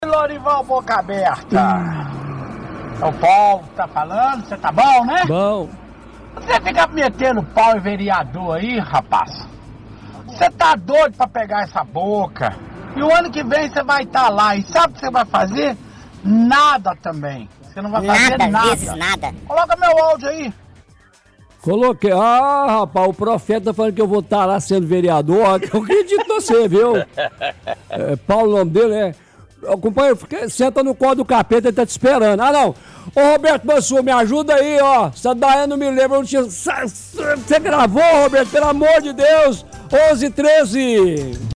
Áudio antigo de ouvinte